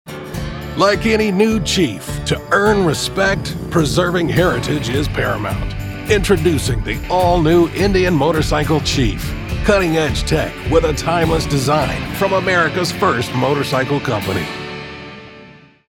Indian Motorcycle - Edgy, Bold, Manly